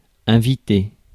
Ääntäminen
IPA : /ˈɪn.vaɪt/